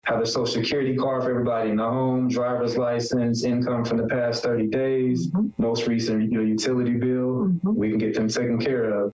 1490 WDAN’s Community Connection program